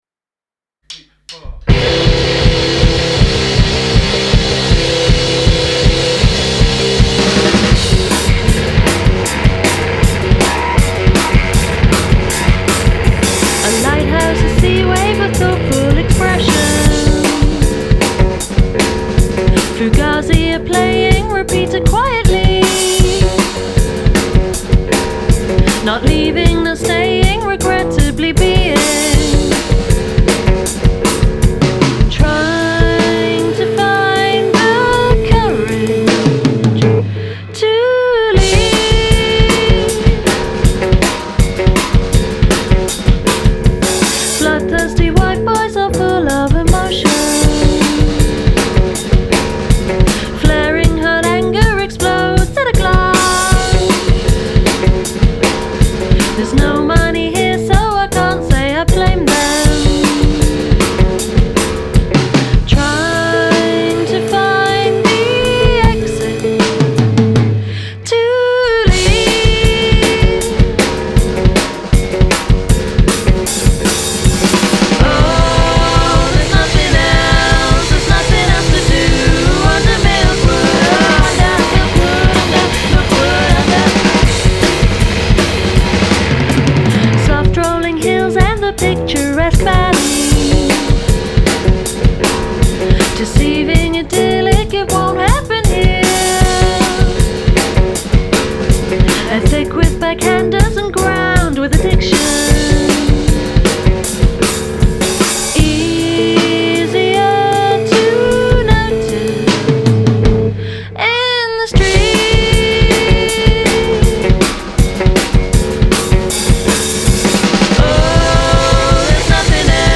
Melodic and energetic duo.